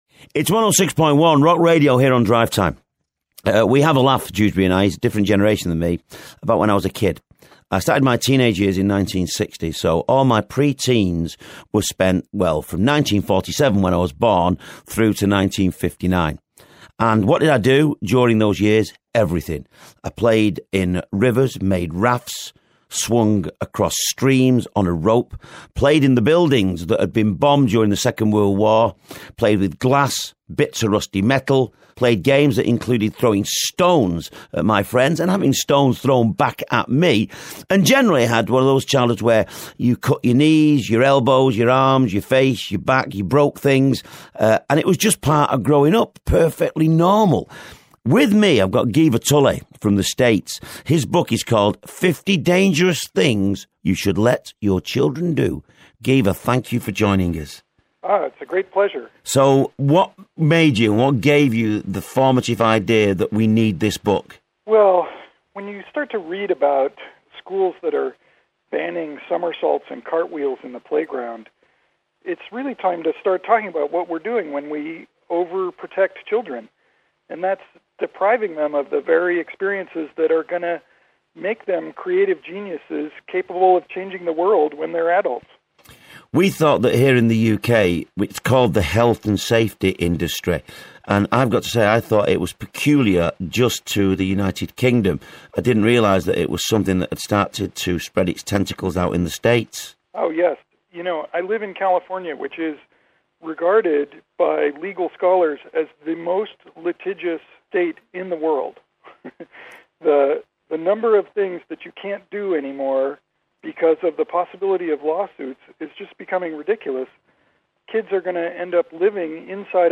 106.1 Rock Radio (Manchester, UK), Drivetime: Interview